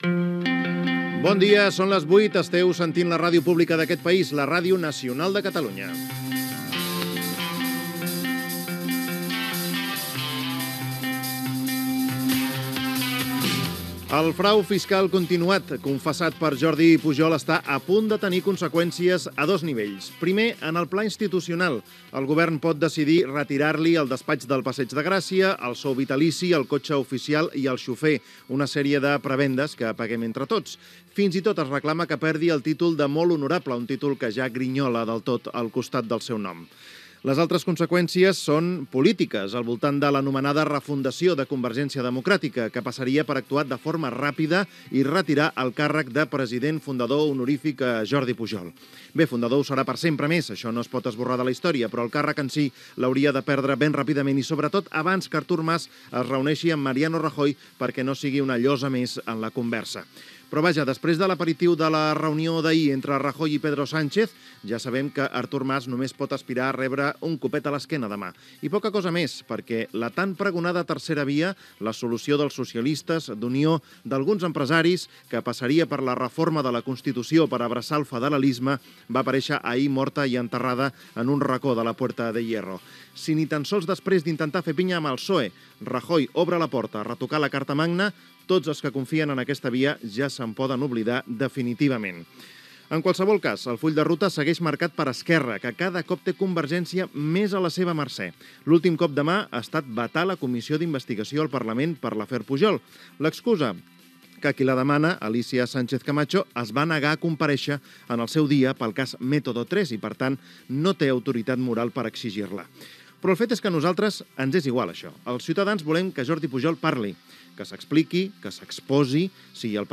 Hora, identificació del programa, editorial sobre les conseqüències del frau fiscal de Jordi Pujol i la situció política del moment. Sintonia de l'emissora
Info-entreteniment